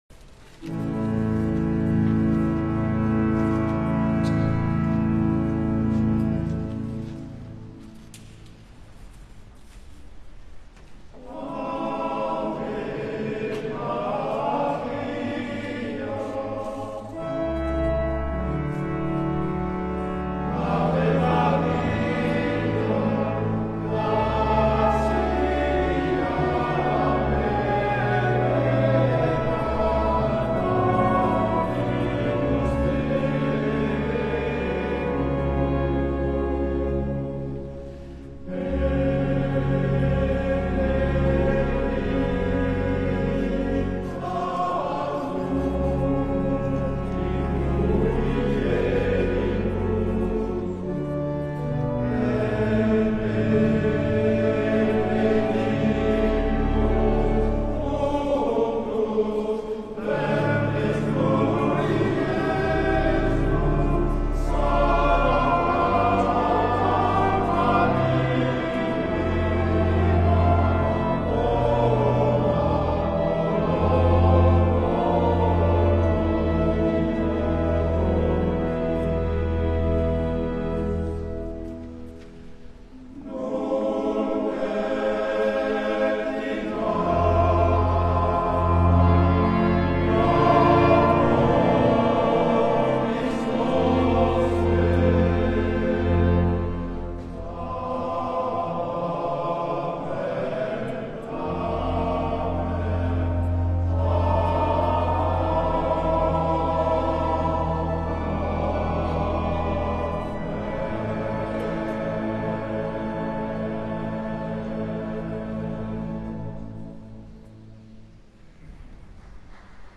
Version originale
par Saint-Louis de Versailles
H10450-Live.mp3